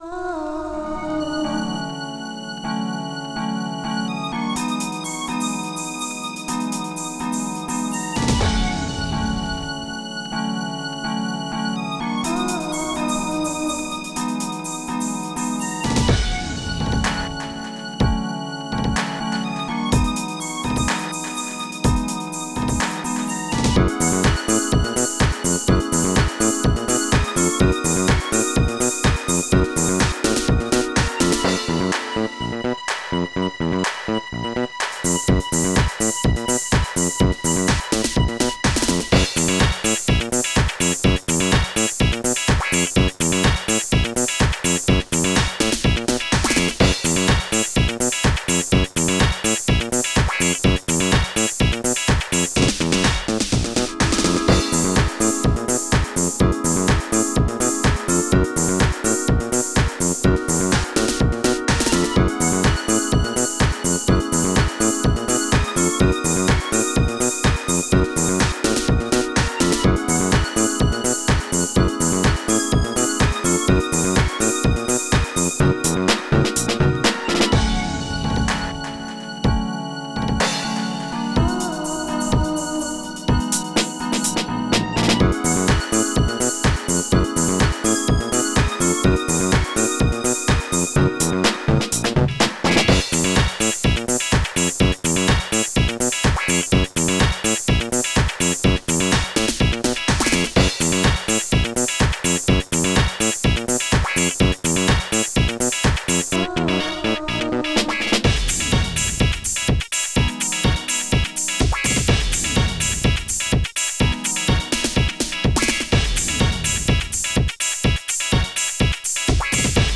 Protracker Module  |  1999-01-20  |  170KB  |  2 channels  |  44,100 sample rate  |  3 minutes, 7 seconds
Protracker and family
stb:snare dance
stb:crash
stb:nfg piano
stb:Clap
stb:scratch
pye:VOICE
pye:ooo...woman (zd)